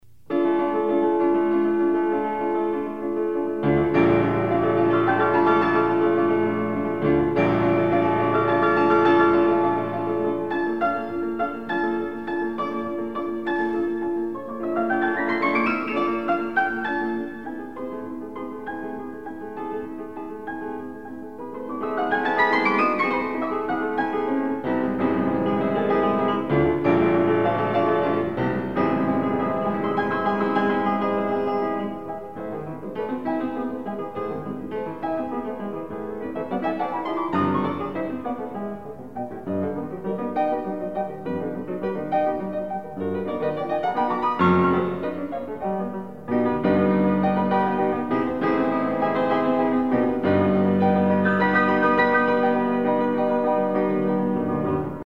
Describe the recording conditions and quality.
Additional Date(s)Recorded September 13, 1977 in the Ed Landreth Hall, Texas Christian University, Fort Worth, Texas Short audio samples from performance